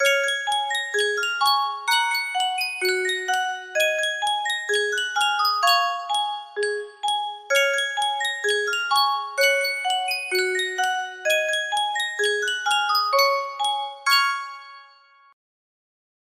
Sankyo Music Box - Five Little Speckled Frogs TBF music box melody
Full range 60